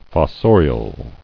[fos·so·ri·al]